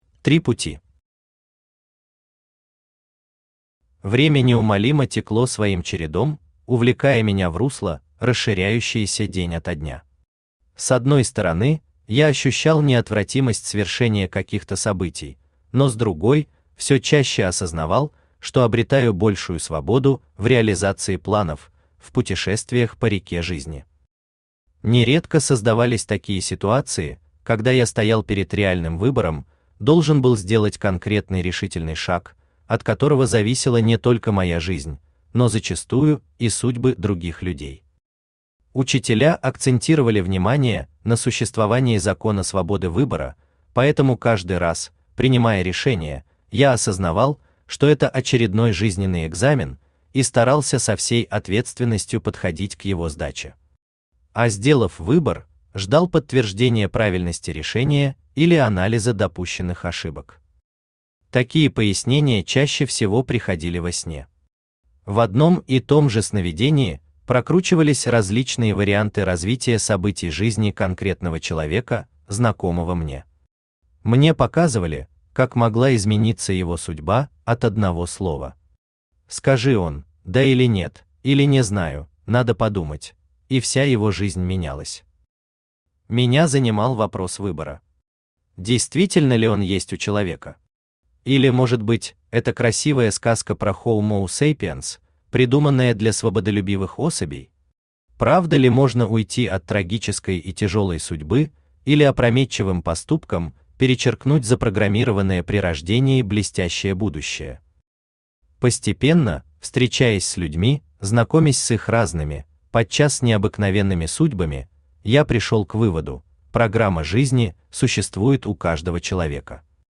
Аудиокнига Арка Им. Серия «Я есмь». Часть II | Библиотека аудиокниг
Часть II Автор Владимир Федорович Ломаев Читает аудиокнигу Авточтец ЛитРес.